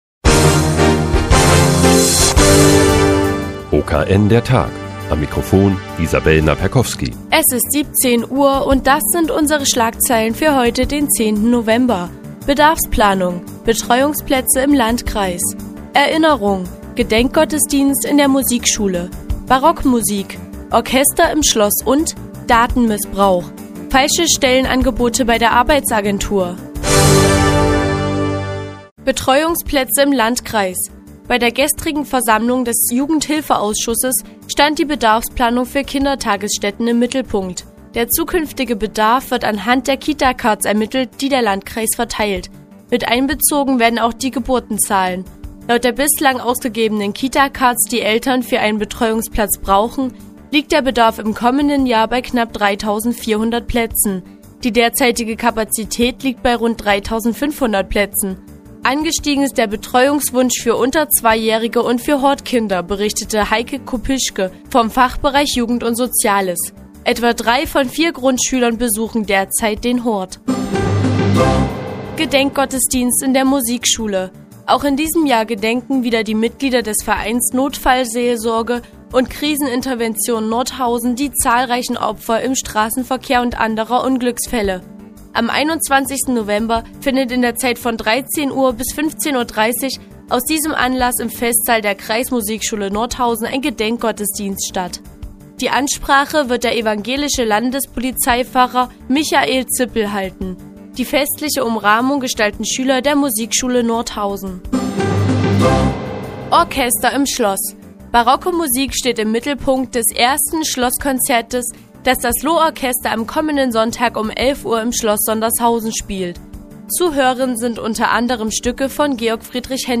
Die tägliche Nachrichtensendung des OKN ist nun auch in der nnz zu hören. Heute geht es um die Bedarfsplanung für Kindertagesstätten und einen Datenmissbrauch bei der Bundesagentur für Arbeit.